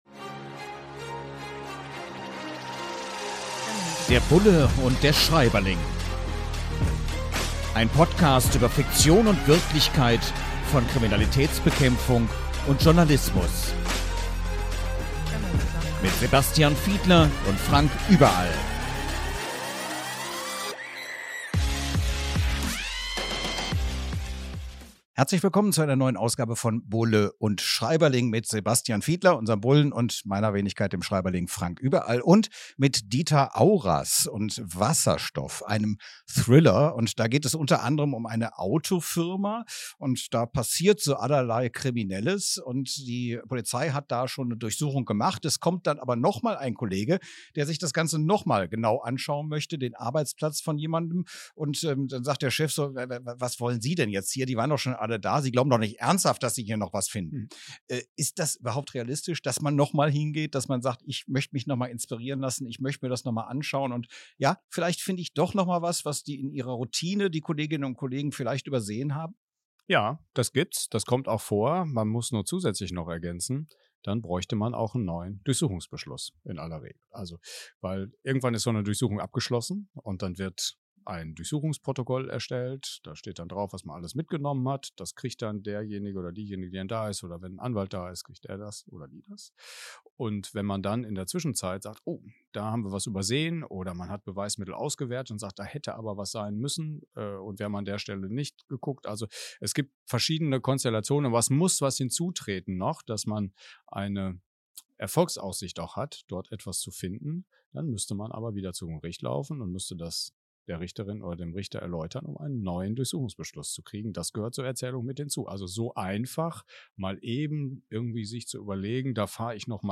In launiger Atmosphäre tauschen sich die Beiden anhand von Aspekten aus, die in dem Buch beschrieben werden.